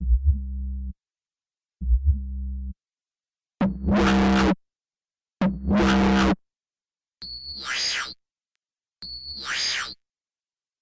Step 2 – Multi-Band Distortion
Here are the three bands played individually:
You can hear that most of the energy is coming from the wide middle band, with the bottom and top just adding a little sub and top end noise.